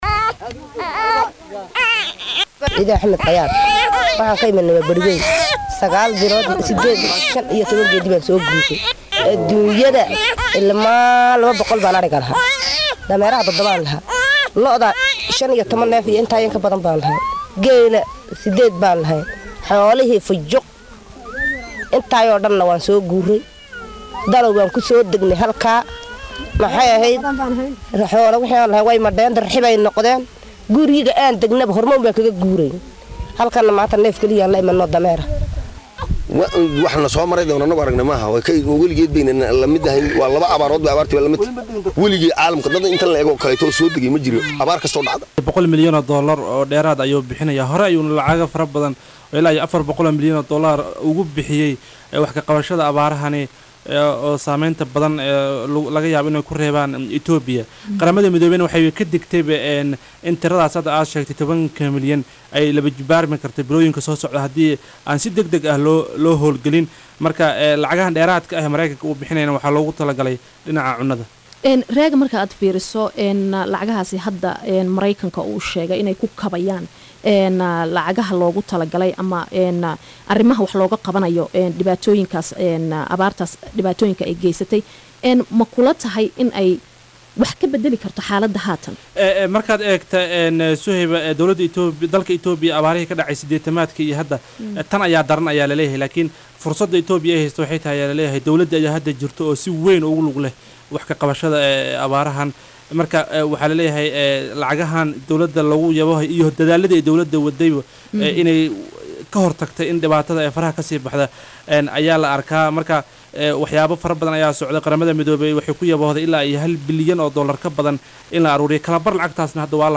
DHAGEYSO-WAREYSI_-Mareykanka-oo-Itoobiya-ka-Caawinayo-Abaaraha-iyo-Xaalad-adag-oo-laga-soo-sheegayo-Dalkaasi-_.mp3